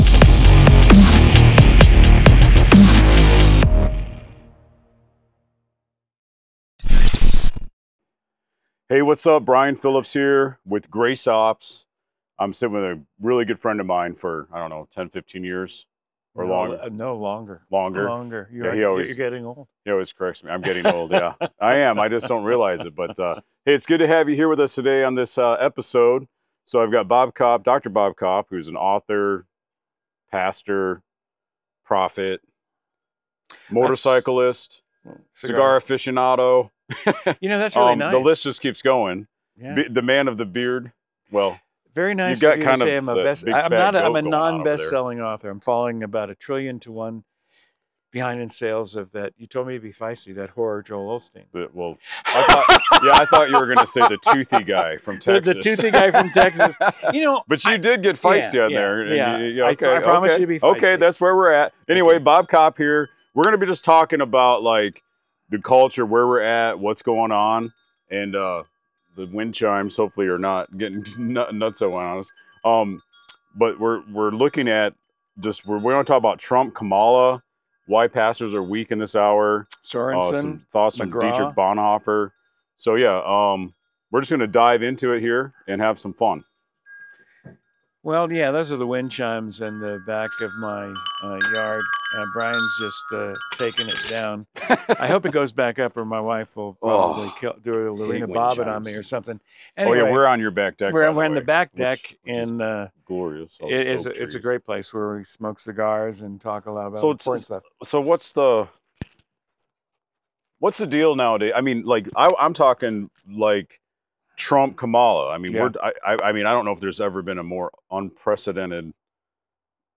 This episode explores the intersection of faith and politics, encouraging Christians to be bold, discerning, and stand firm in their values when it comes to voting. Join us for a candid and thought-provoking discussion on how faith should influence our political decisions.